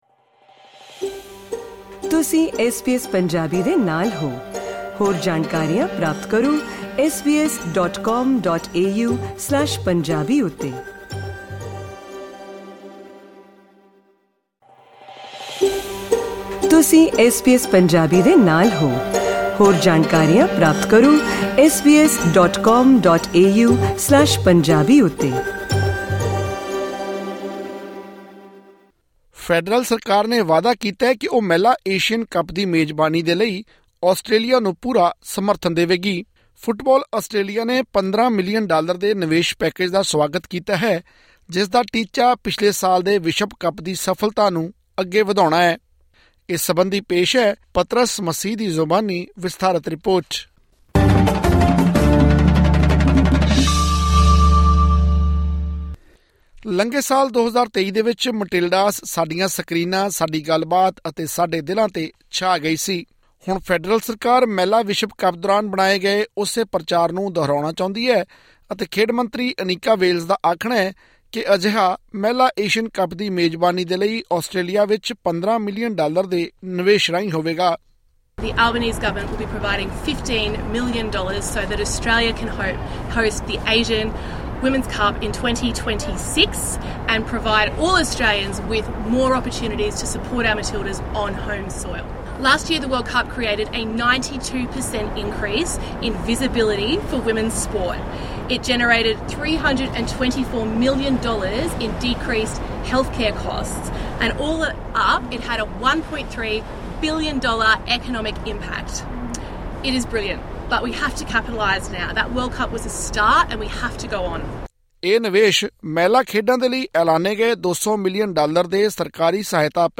ਸਾਲ 2026 ਵਿੱਚ ਇਸ ਕੱਪ ਦੀ 50ਵੀਂ ਵਰ੍ਹੇਗੰਢ ਮਨਾਈ ਜਾਵੇਗੀ ਅਤੇ ਇਸ ਦੇ ਲਈ ਆਸਟ੍ਰੇਲੀਆ ਵਿੱਚ 12 ਦੇਸ਼ ਇਕੱਠੇ ਹੋਣਗੇ। ਹੋਰ ਵੇਰਵੇ ਲਈ ਸੁਣੋ ਇਹ ਰਿਪੋਰਟ...